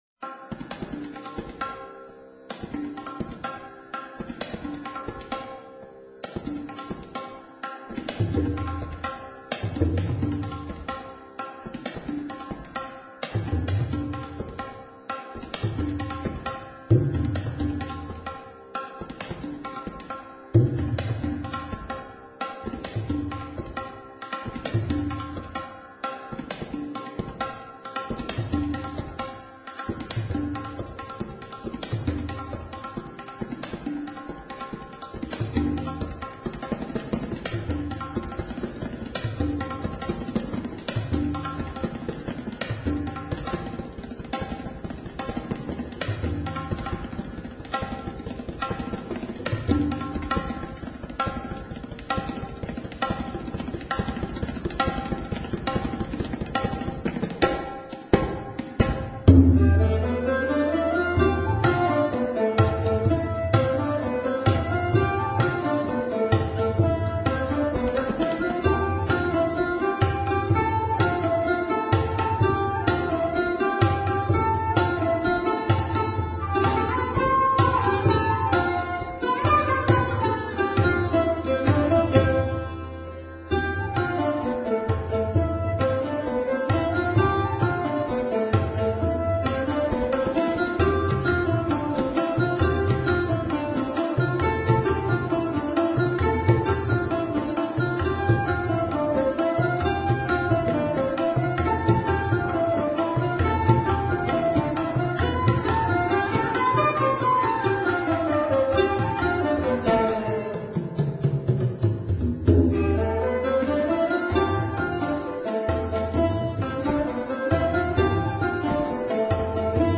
Sarod
Bass-, Quer-, Bambusflöte